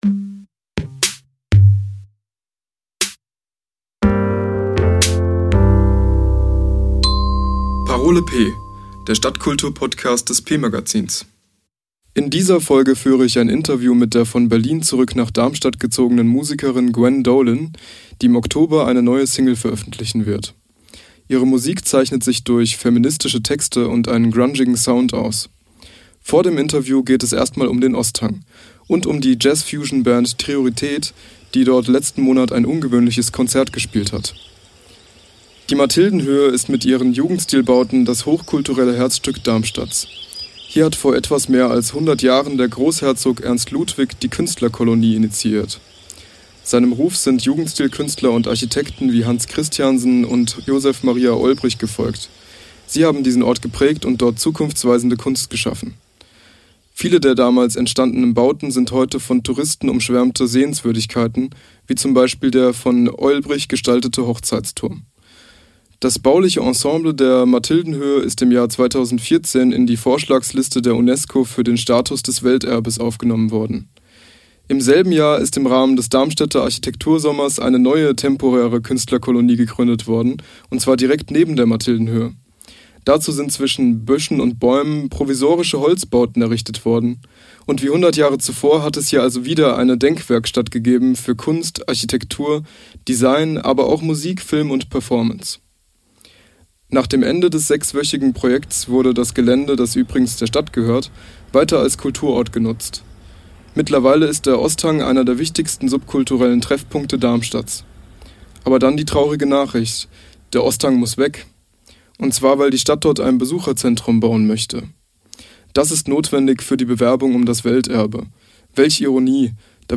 Wir sind am Osthang und treffen die Jazz-Fusion-Band Triorität nach einem denkwürdigen Konzert. Außerdem im Interview